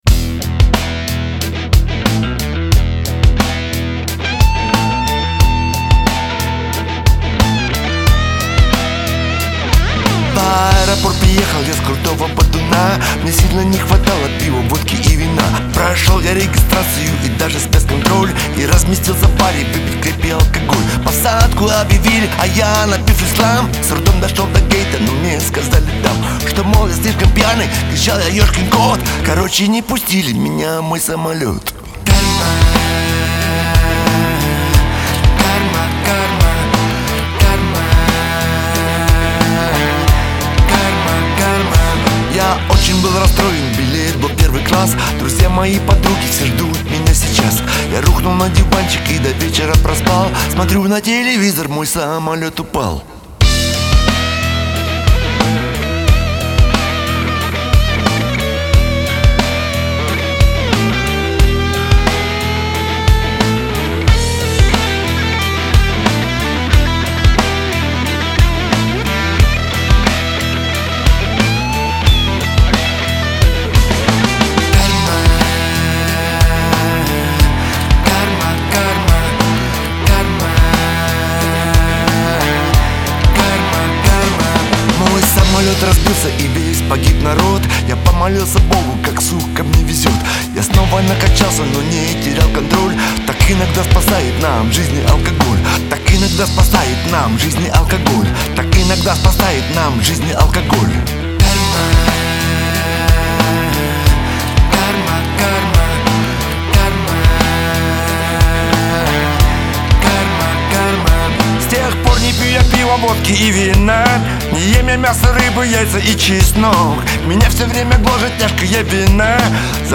Жанры: рок-музыка, блюз, джаз, фьюжн,
поп-рок, новая волна, хард-рок, синтипоп